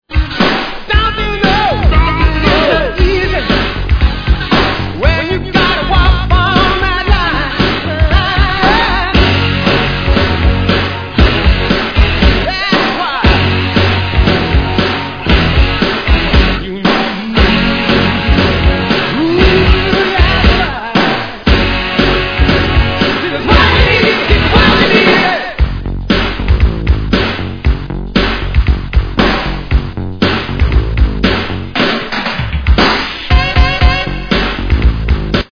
Tag       OTHER ROCK/POPS/AOR